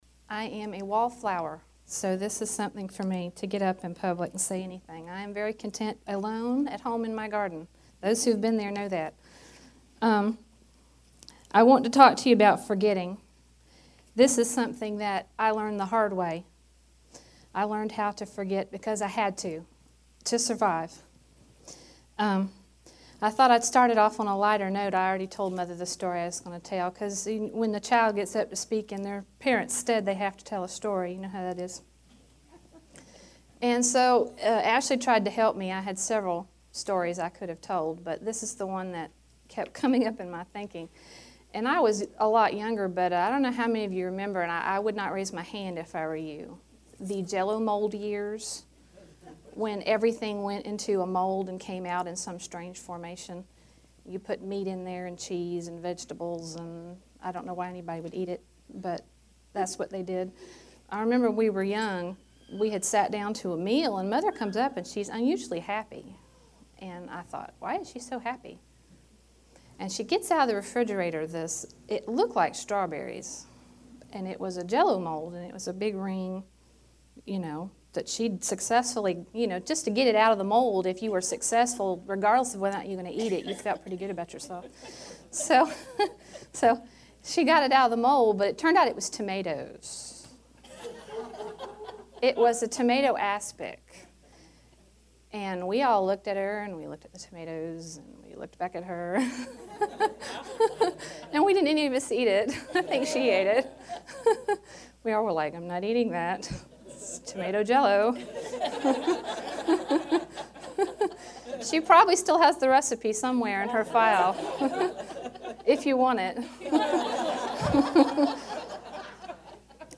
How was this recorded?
In 2010, I spoke for a ladies’ Bible Study and gave a portion of my testimony and one of the most important steps toward my mental healing, how to forget.